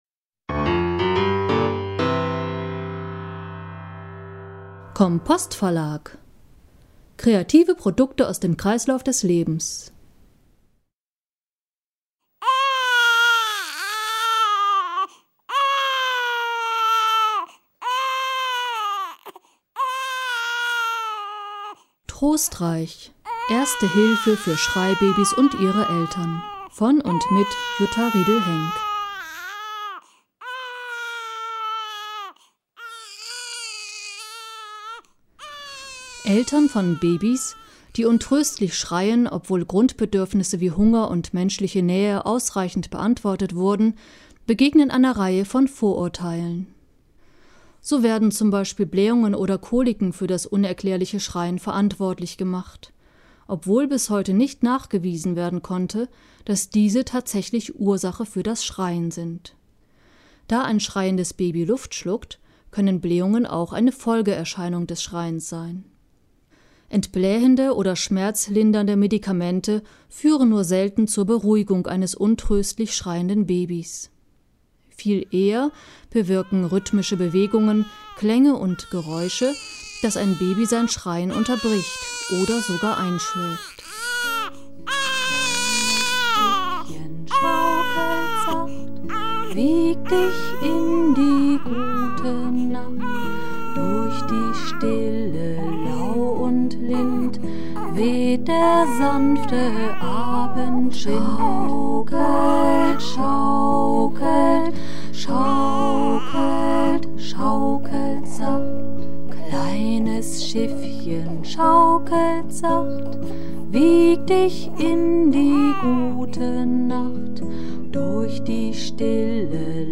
Trostreich Hörbuch